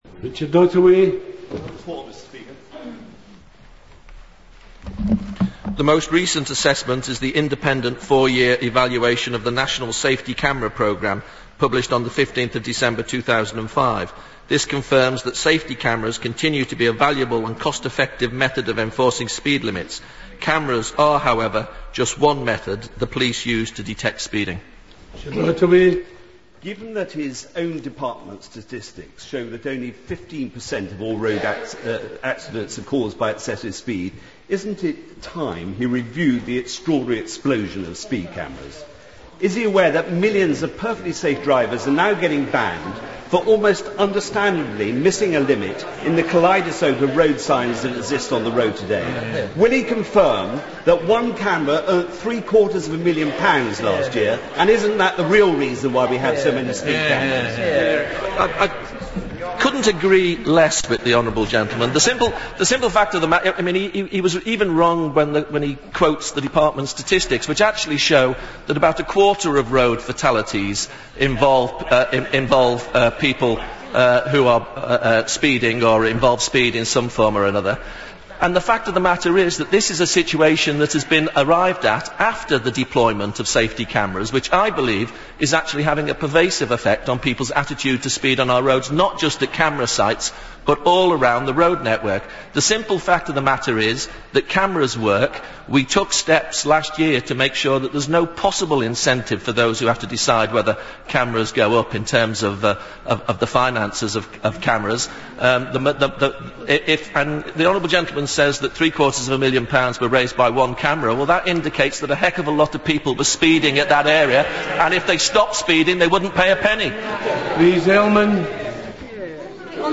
Catch it if you can and hear Ladyman stuttering and spluttering.
Richard Ottaway asked the second question verbally and gets a really rather fluffed and deliberately misleading response from Dr Ladyman.